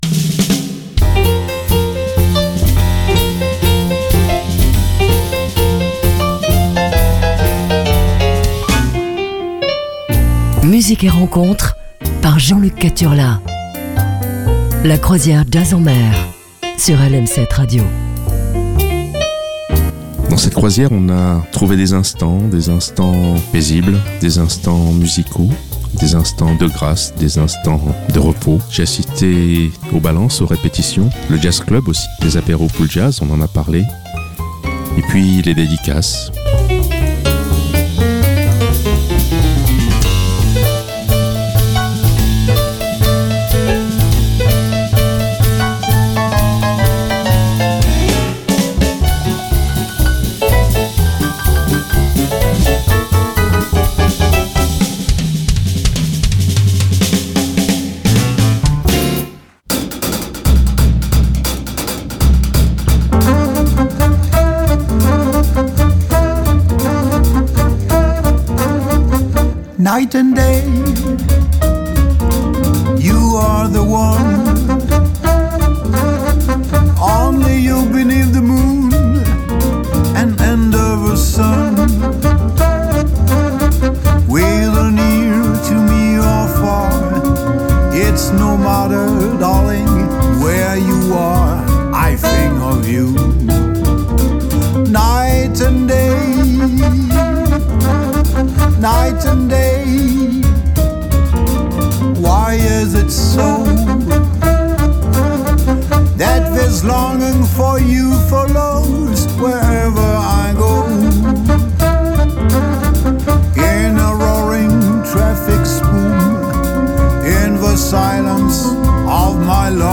passer une demi-heure sur des rythmes jazzy